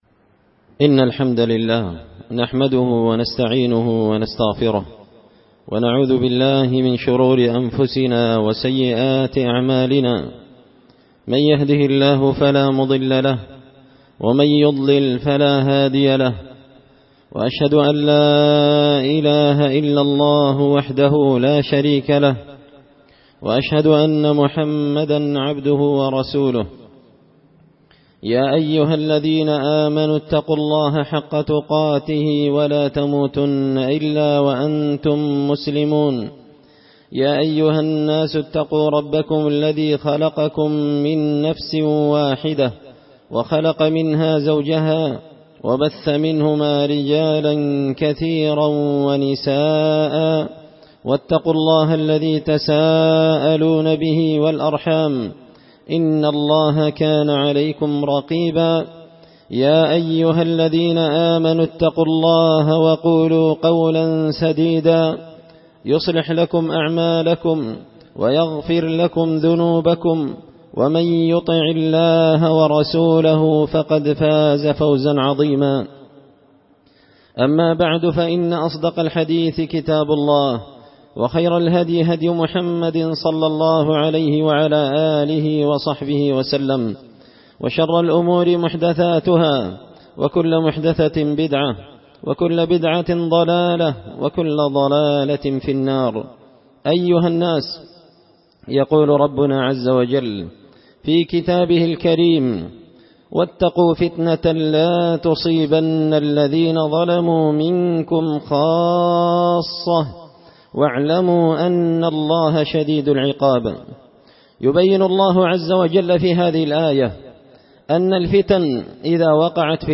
خطبة جمعة بعنوان – أسباب الوقوع في الفتن وسبل السلامة منها
دار الحديث بمسجد الفرقان ـ قشن ـ المهرة ـ اليمن